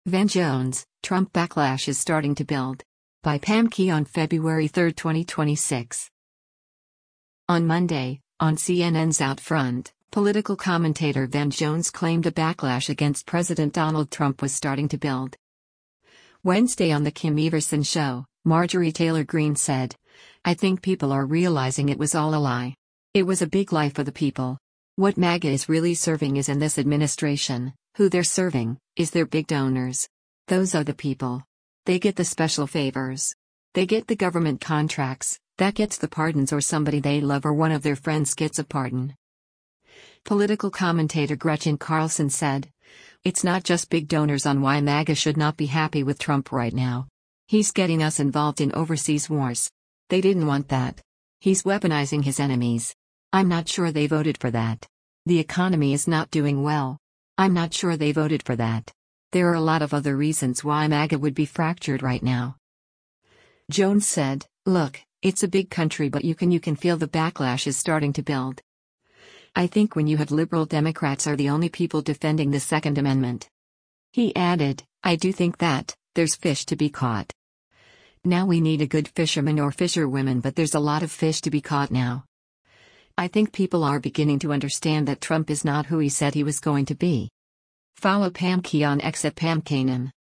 On Monday, on CNN’s “OutFront,” political commentator Van Jones claimed a backlash against President Donald Trump was starting to build.